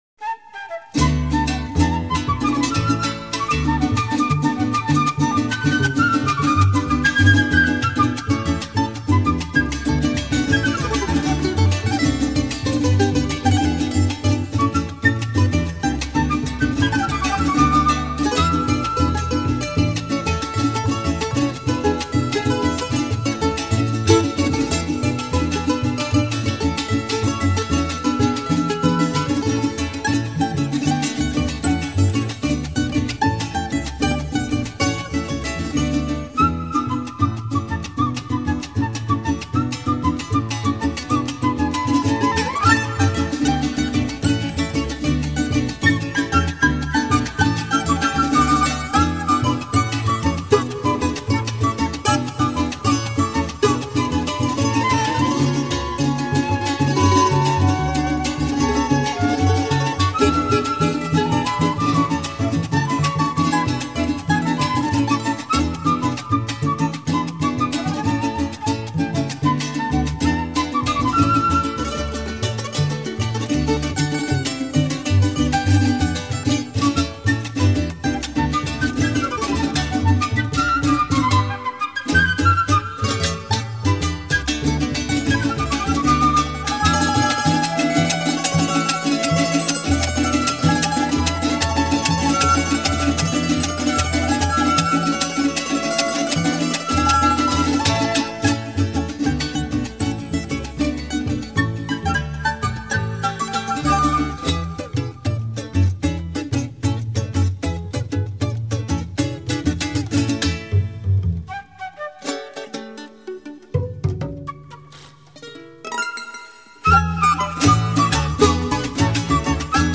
La música de América Latina